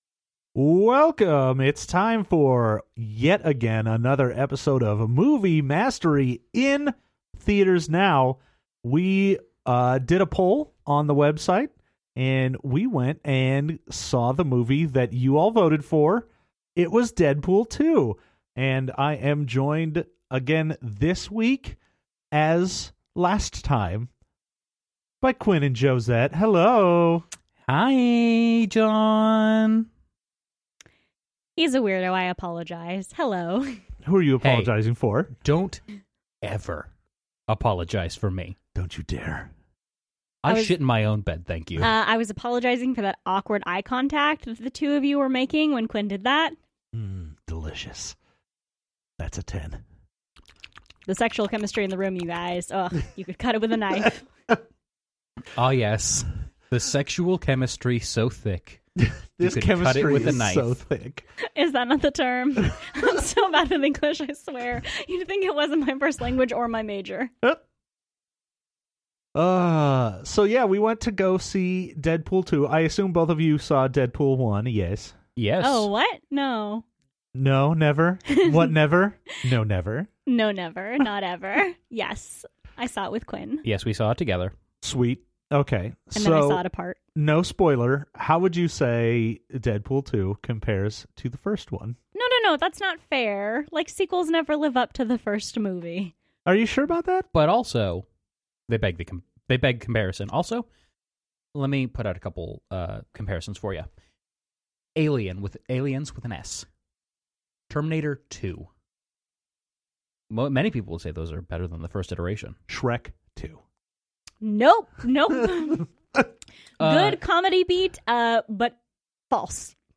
Though mostly it's our thoughts on weird mouth sounds and talking like a baby.